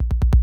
7 Boiling In Dust Kick Roll.wav